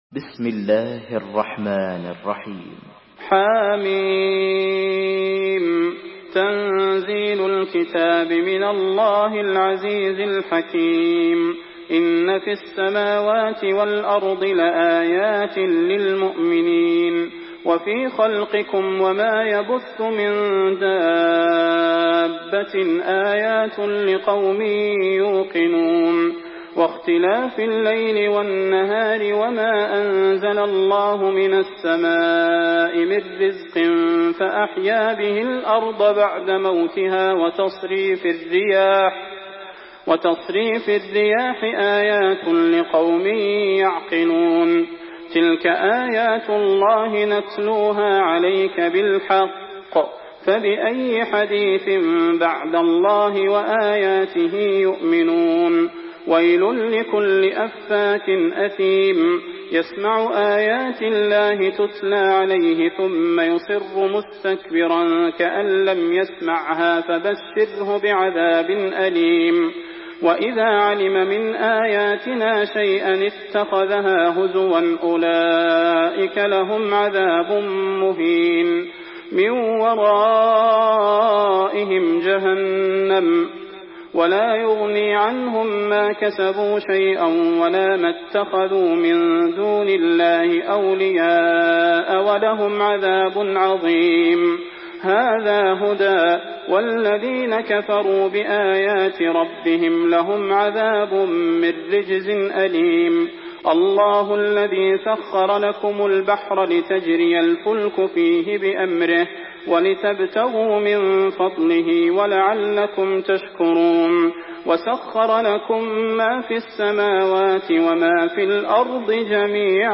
Surah Al-Jathiyah MP3 by Salah Al Budair in Hafs An Asim narration.
Murattal Hafs An Asim